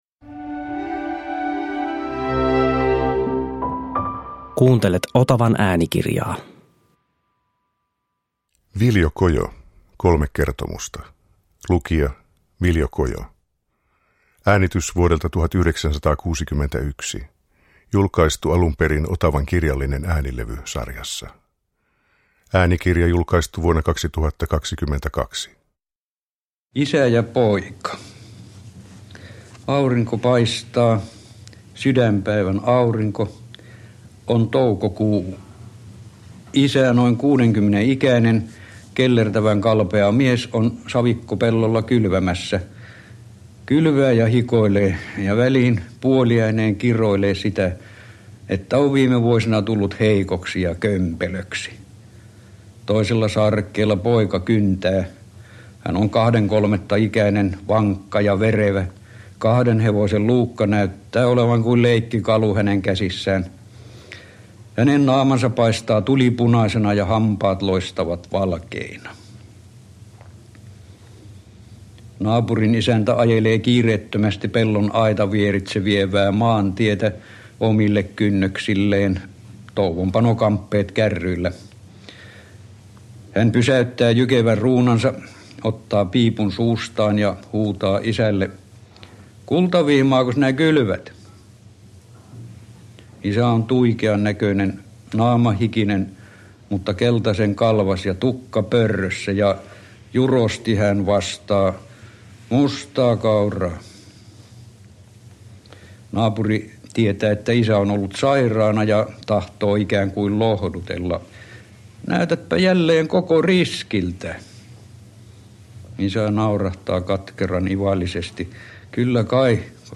Tässä äänikirjassa kuuluu Viljo Kojon oma, rehevästi kertoileva ääni sekä suomalaisen pientilallisen elämä hevosineen, hellatulineen ja maatöineen. Kojo kertoo novellinsa Isä ja poika, Ei tarvinnut jonottaa ja Kuolu-unet.